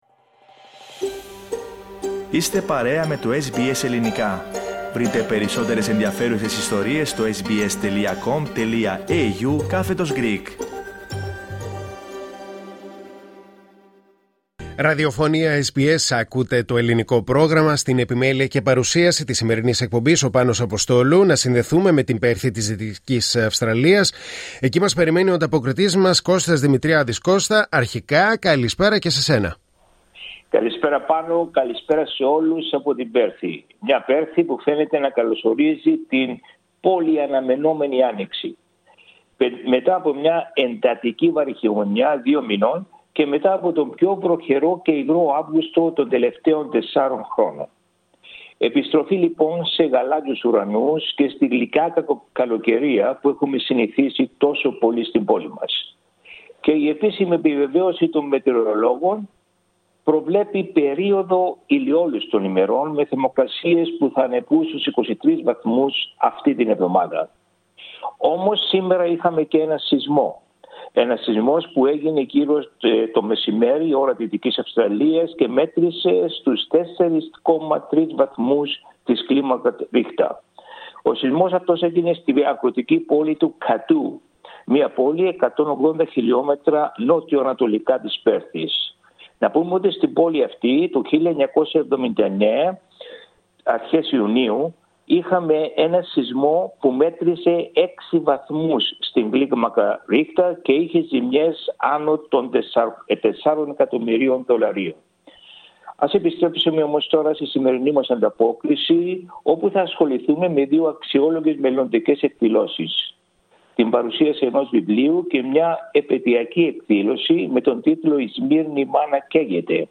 Ακούστε την εβδομαδιαία ανταπόκριση από την Δυτική Αυστραλία.